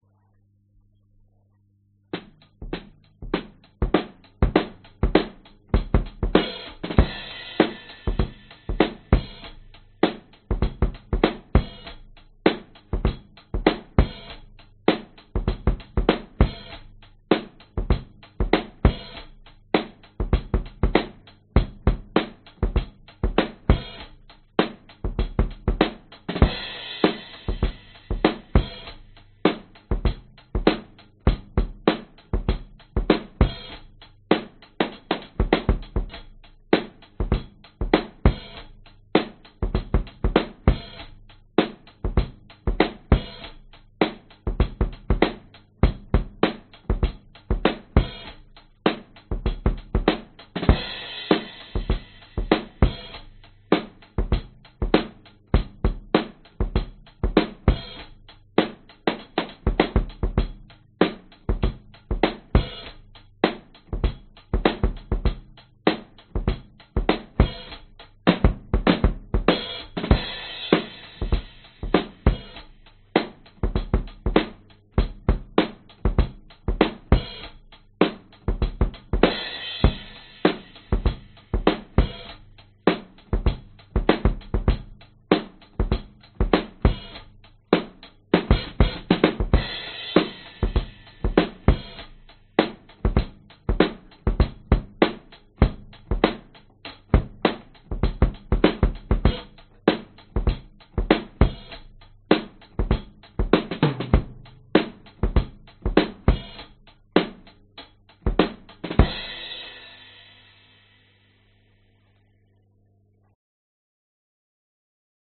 爵士鼓